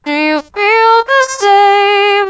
Vocoding Approach
Below are a number of audio examples that demonstrate the vocoding approach, i.e. modifying the harmonic distribution generated from a timbre transfer decoder. Two models were used; on trained on brass instruments, and another on a harsh synthesizer.
vc-brass-0.7.wav